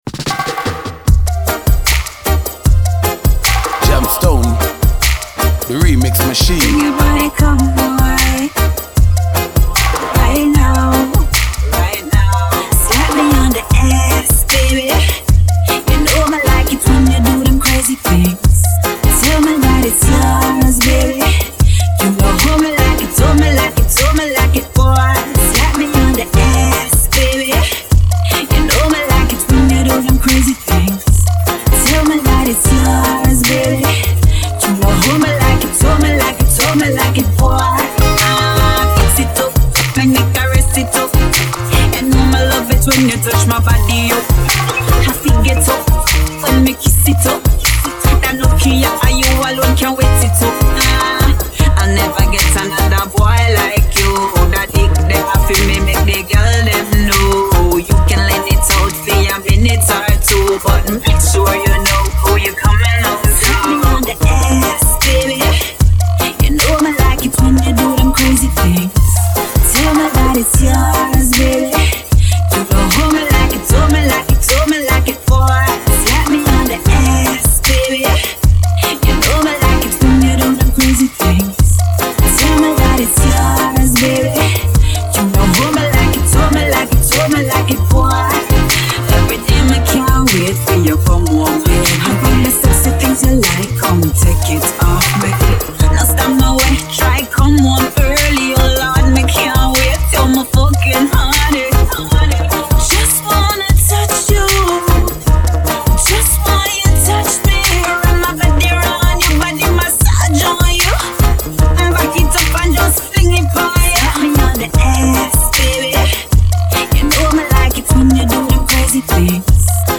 Vocal track
riddim